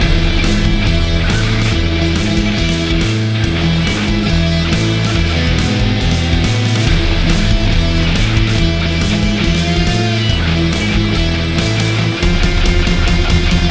Warning: This artwork incorporates iAMF frequency elements intended for subconscious conditioning.
These ‘Infinity L00p’ soundscapes, crafted with precision, resonate with the listener’s subconscious, creating an auditory experience that lingers long after the final note.